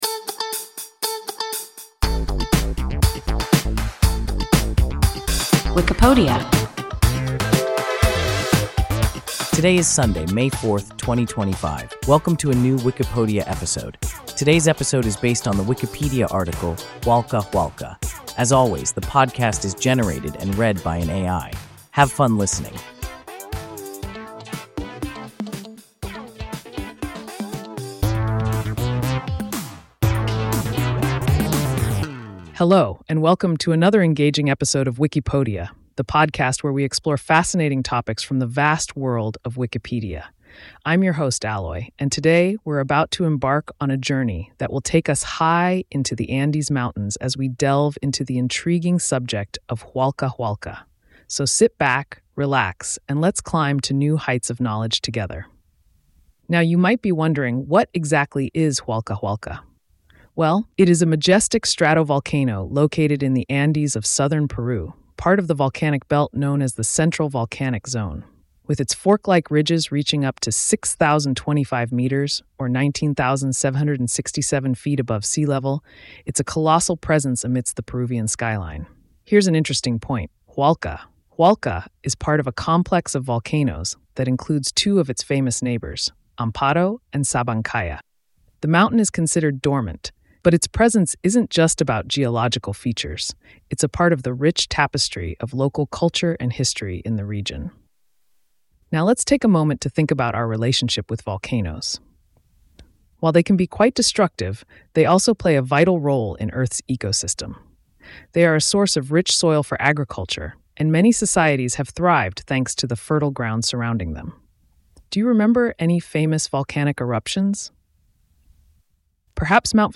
Hualca Hualca – WIKIPODIA – ein KI Podcast